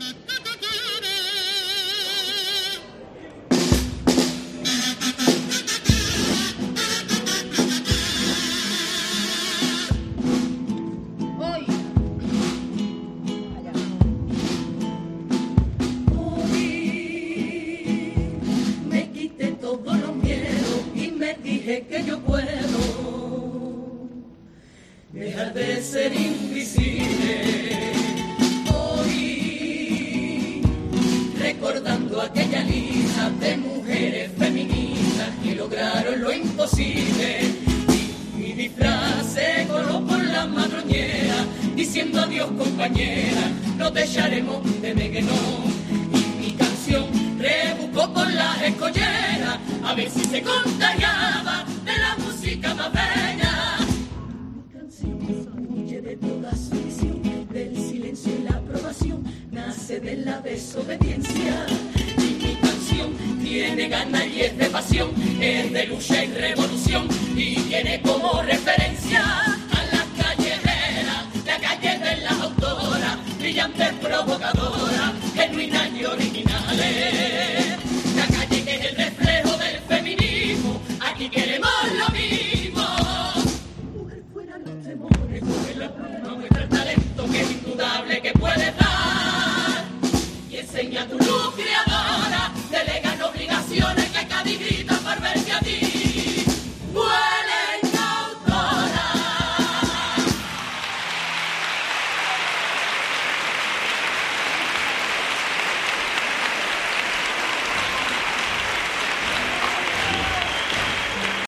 Uno de los pasodobles de la comparsa femenina 'We can do Carnaval' se convierte en una de las coplas más destacadas de la segunda función clasificatoria
Pasodoble de la comparsa 'We can do Carnaval'
Una agrupación íntegramente femenina que espera no ser la única en los próximos carnavales.